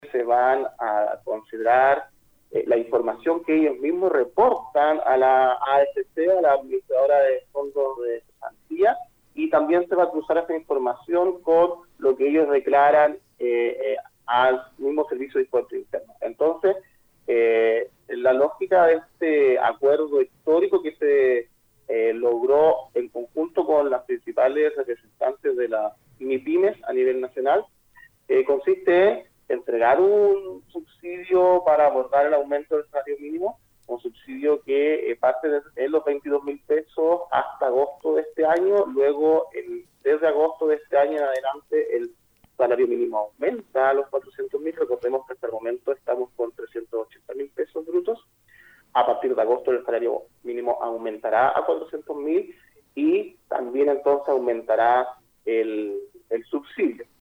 En esta línea, el seremi de Economía, Javier Sepúlveda, abordó en Nuestra Pauta los avances en la implementación de las veintiún medidas que conforman este programa.